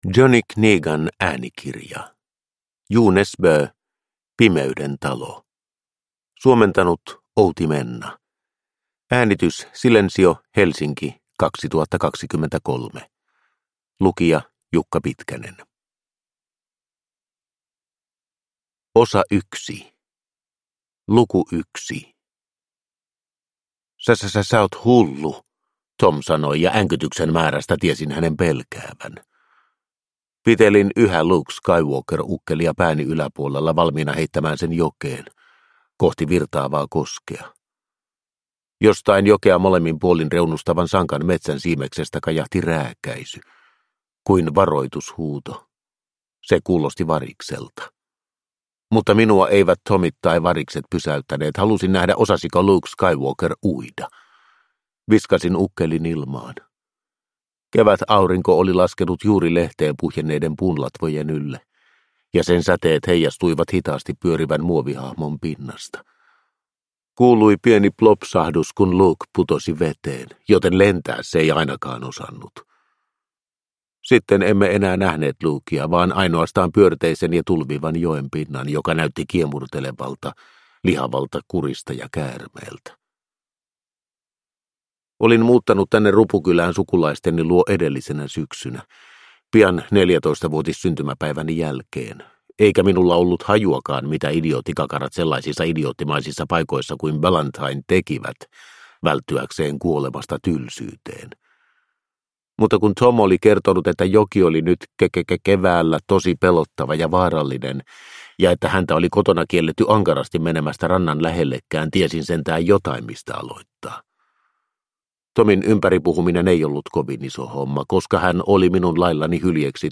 Pimeyden talo – Ljudbok – Laddas ner